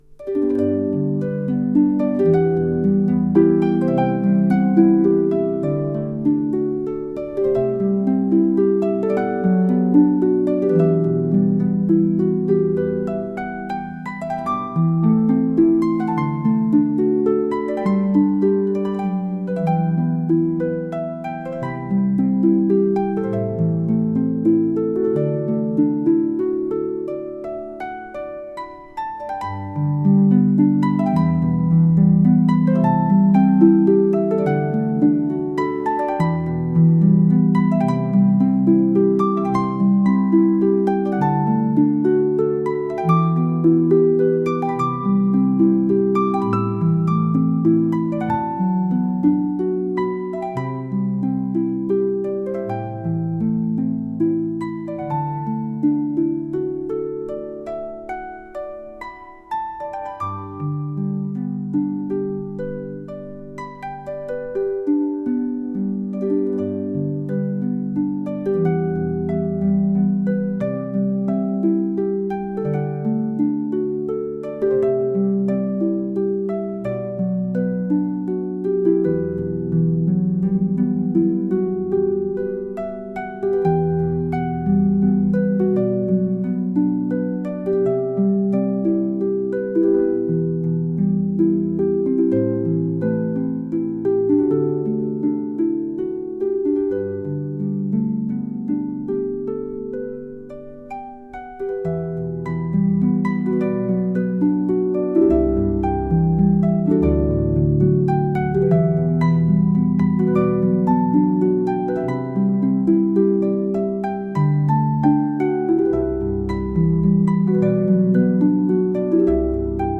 Calm Music